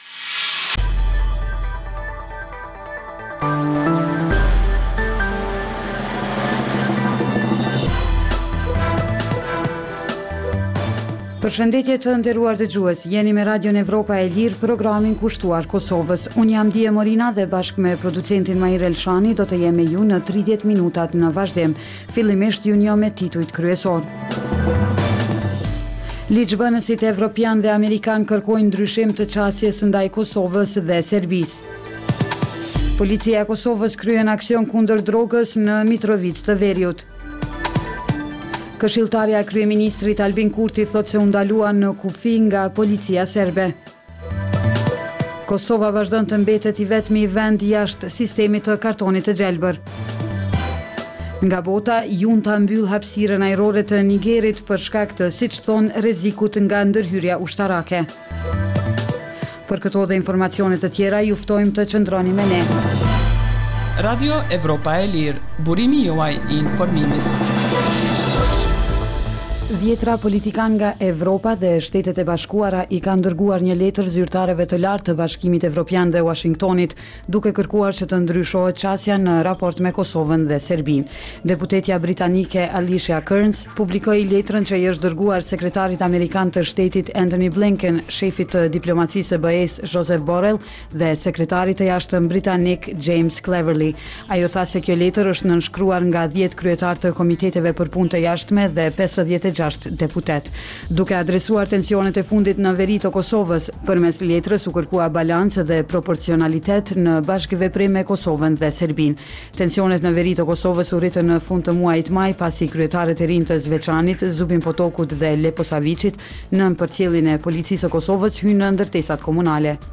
Emisioni i orës 16:00 është rrumbullaksim i zhvillimeve ditore në Kosovë, rajon dhe botë. Rëndom fillon me kronikat nga Kosova dhe rajoni, dhe vazhdon me lajmet nga bota. Kohë pas kohe, në këtë edicion sjellim intervista me analistë vendorë dhe ndërkombëtarë për zhvillimet në Kosovë.